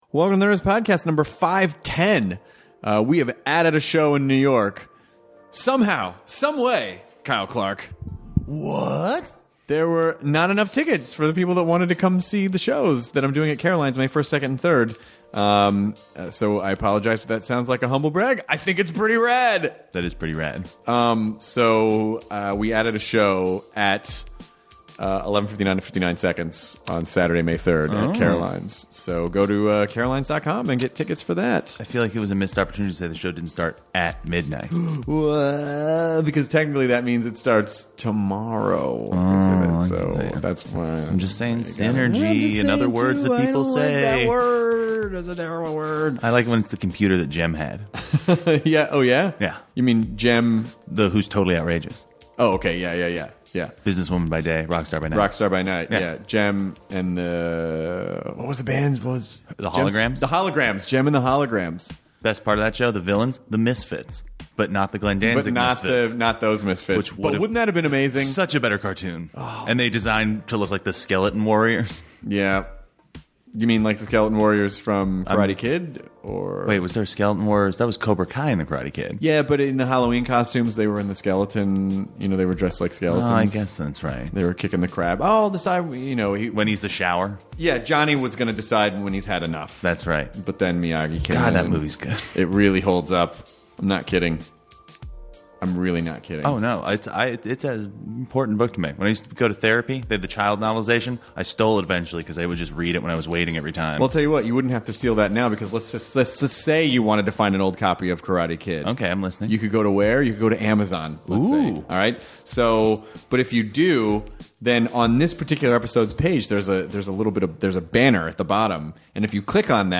The hilarious Lewis Black sits down with Chris to talk about the process of writing “edgy” material, the importance of keeping busy as you get older, his shows being a destination for family outings and his newest special Old Yeller!